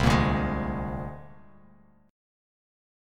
C7sus2#5 chord